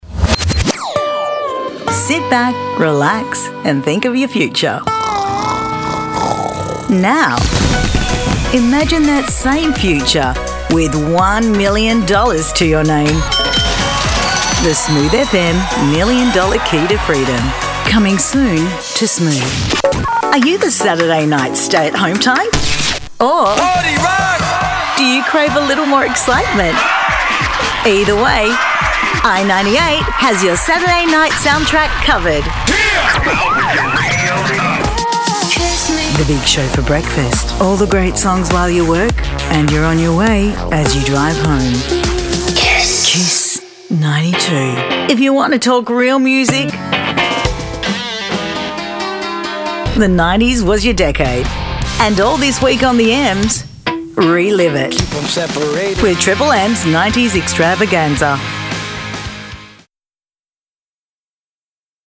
English (Australian)
Radio Imaging